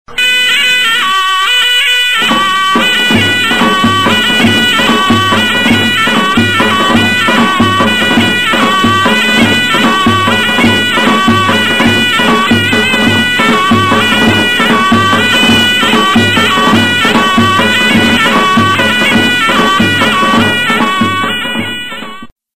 yk-nt---saz-w-dhl-`yd-nwrwz.mp3